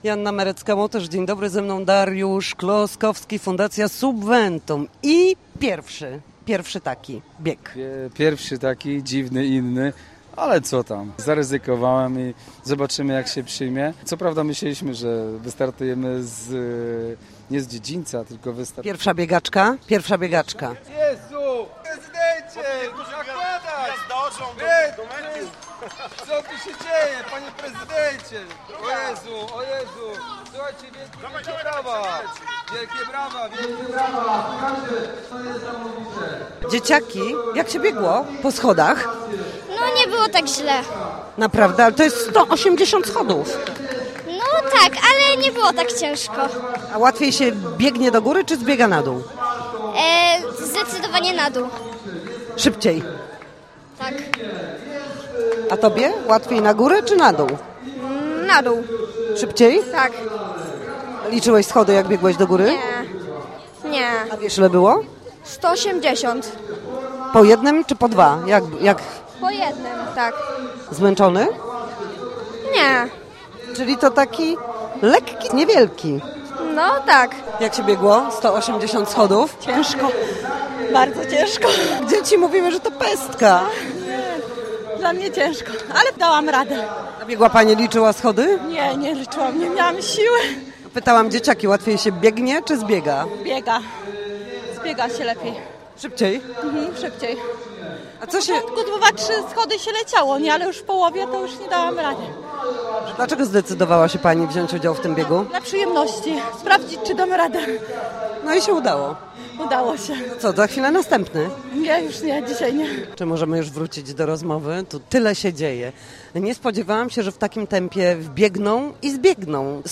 Posłuchaj relacji z imprezy: https